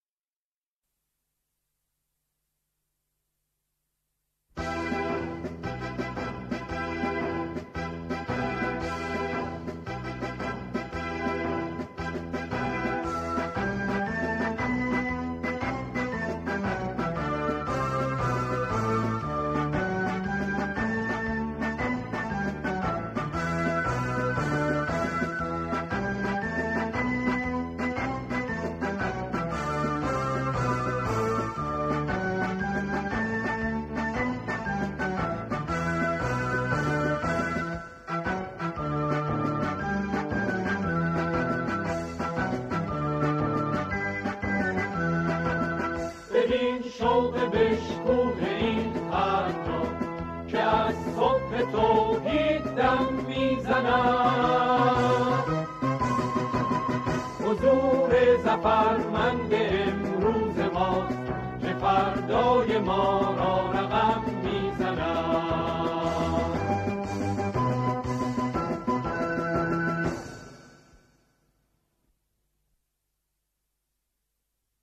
قطعه